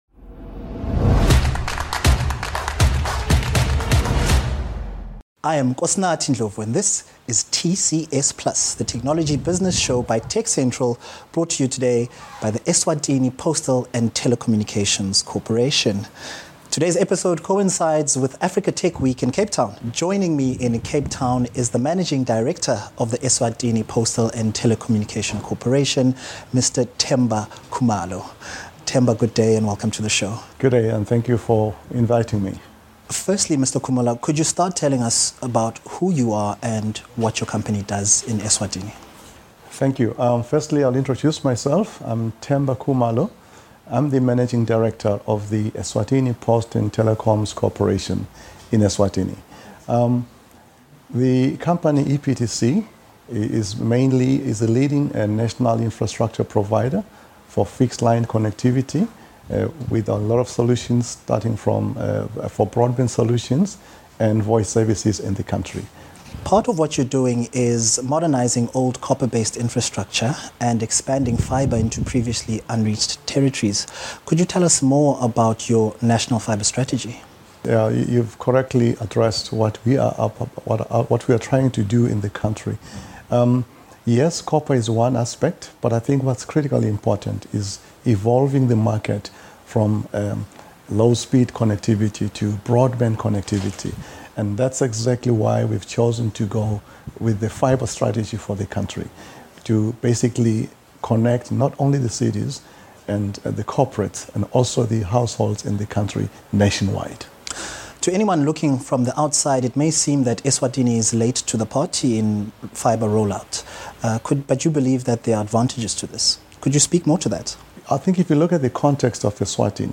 TechCentral's TCS+ is a business technology show that brings you interviews with leaders in South Africa's technology industry - and further afield.